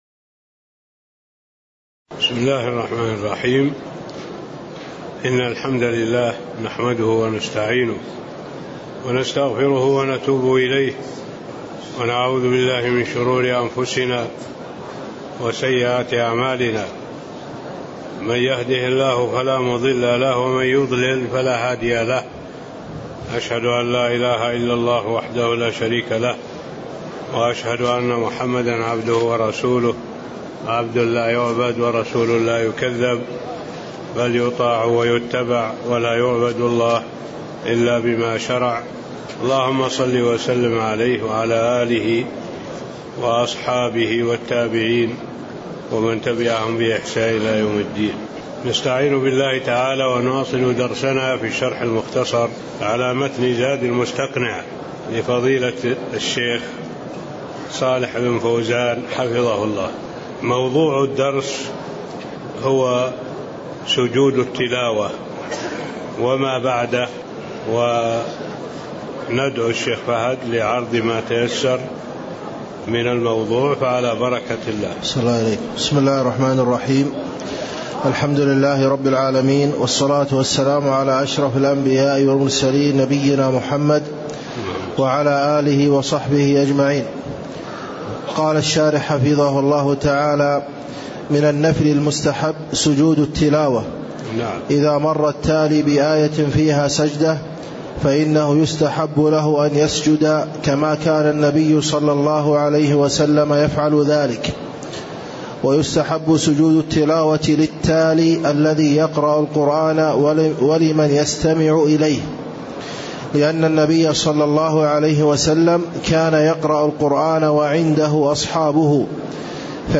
تاريخ النشر ٢٧ جمادى الأولى ١٤٣٤ هـ المكان: المسجد النبوي الشيخ: معالي الشيخ الدكتور صالح بن عبد الله العبود معالي الشيخ الدكتور صالح بن عبد الله العبود باب سجود التلاوة (09)